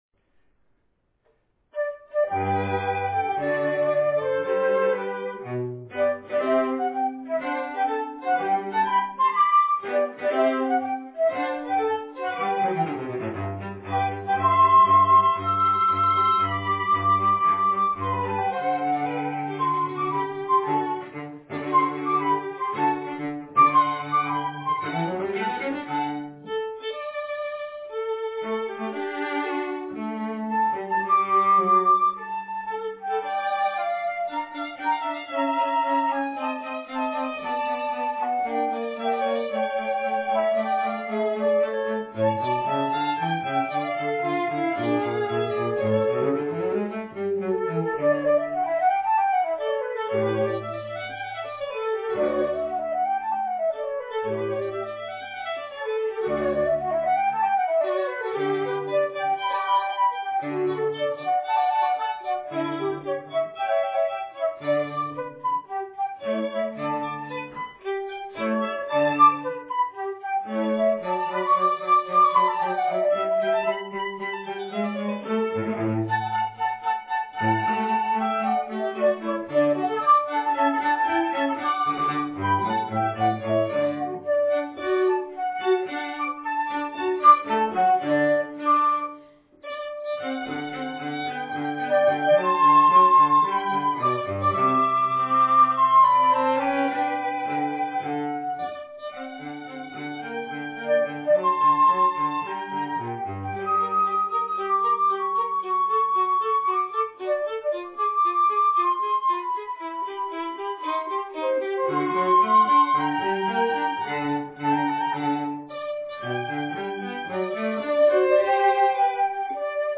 Spiritoso